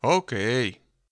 worker_ack3.wav